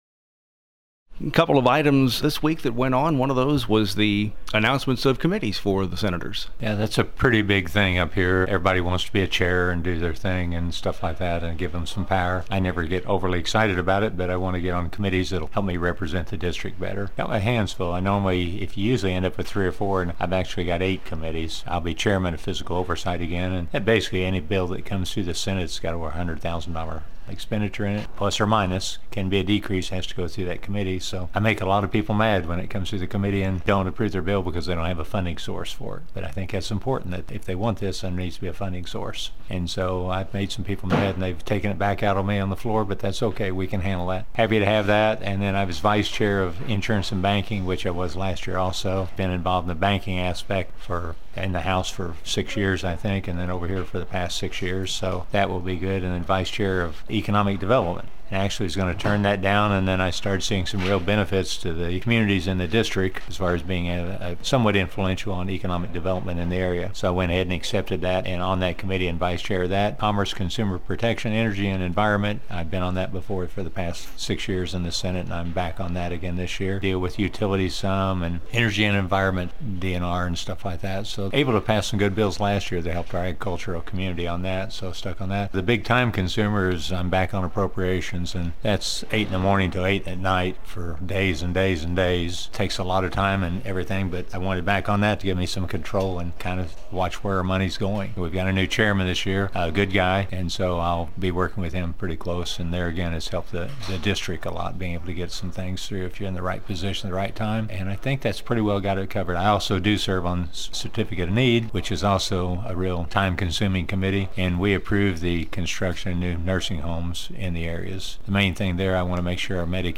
JEFFERSON CITY — State Sen. Mike Cunningham, R-Rogersville, discusses Missouri Senate committees he’ll serve on this year, as well as reaction to this week’s State of the State address.